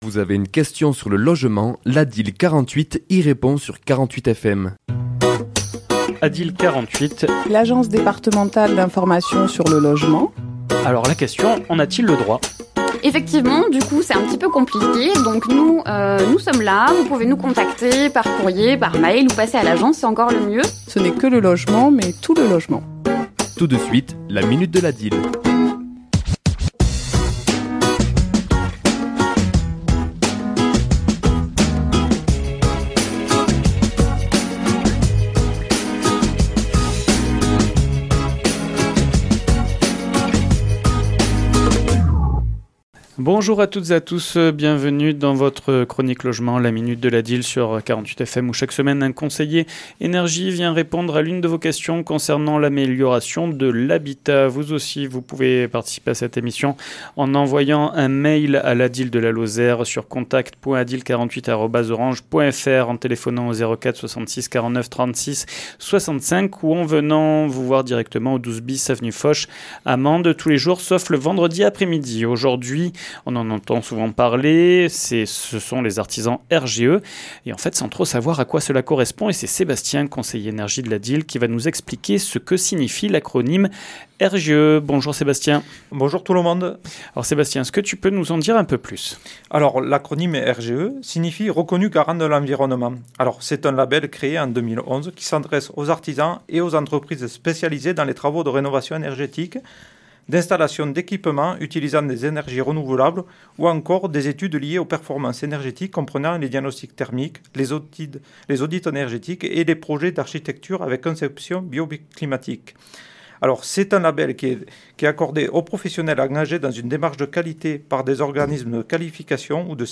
Chronique diffusée le mardi 3 juin à 11h et 17h10